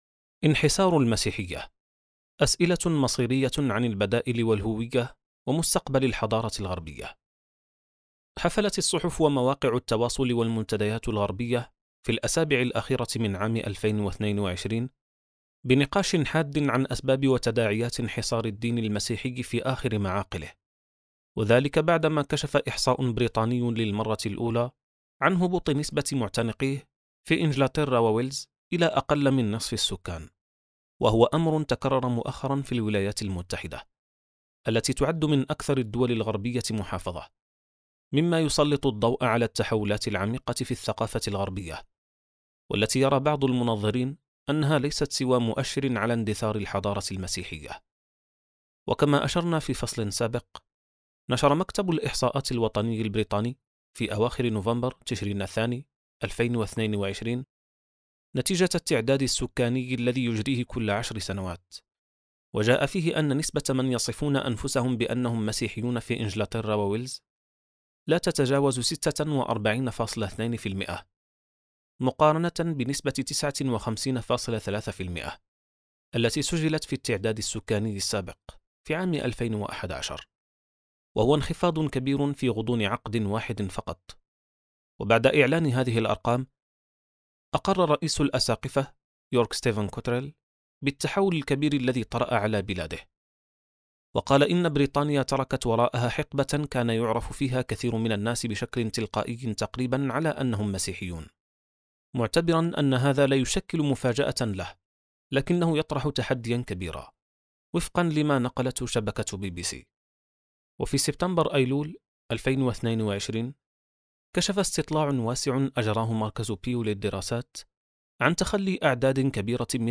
كتاب صوتي | خارطة الطوائف (1419): انحسار المسيحية • السبيل